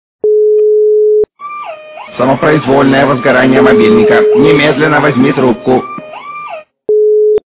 » Звуки » Смешные » Голос инопланетянина - Самопроизвольное возгорание мобильника.
При прослушивании Голос инопланетянина - Самопроизвольное возгорание мобильника. качество понижено и присутствуют гудки.
Звук Голос инопланетянина - Самопроизвольное возгорание мобильника.